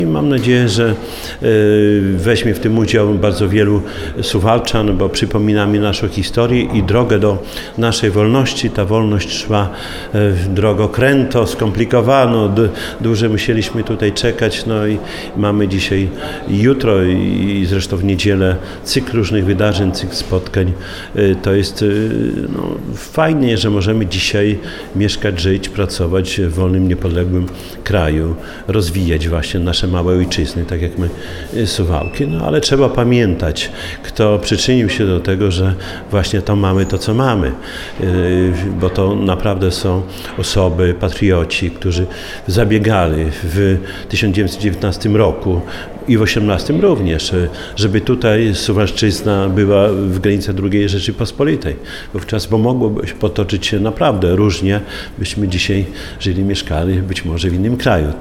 Przed mieszkańcami i gośćmi trzy dni wystaw, akademii oraz wydarzeń sportowych. Do udziału w miejskim święcie zaprasza Czesław Renkiewicz, prezydent Suwałk.